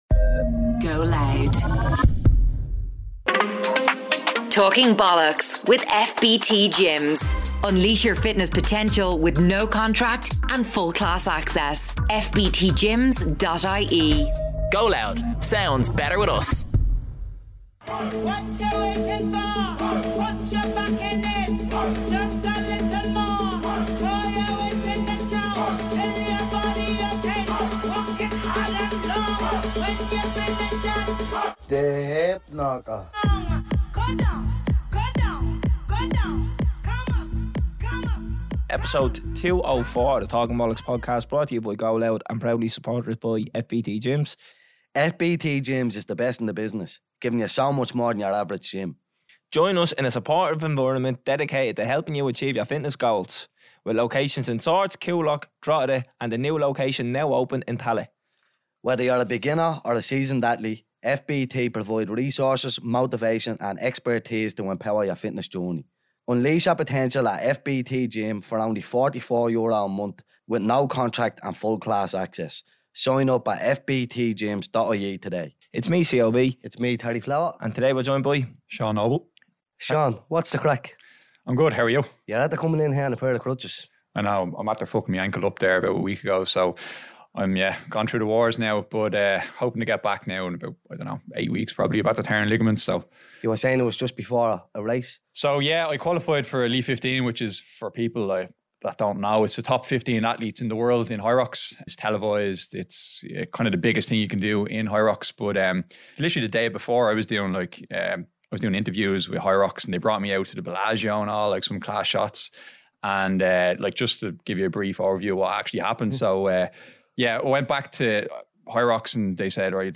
Two lads from the inner city of Dublin sitting around doing what they do best, talking bollox.